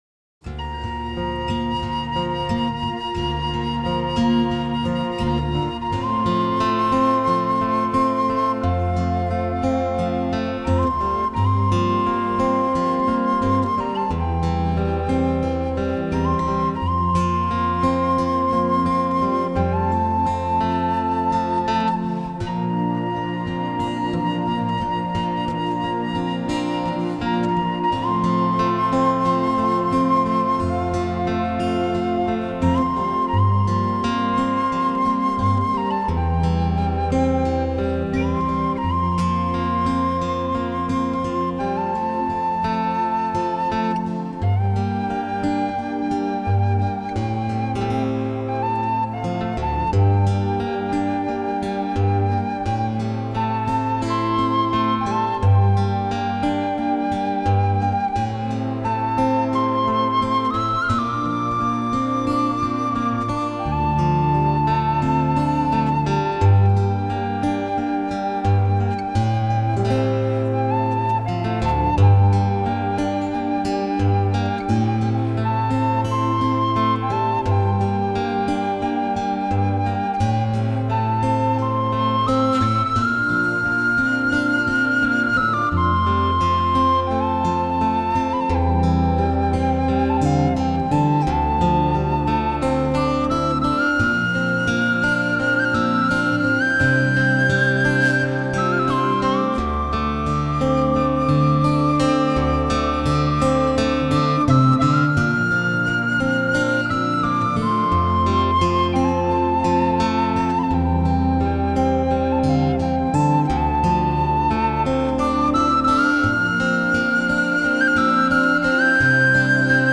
主奏乐器：人声、民族乐器
克尔特音乐新定义·最后的华丽惊叹号！
难得一闻在西班牙的现场演出版，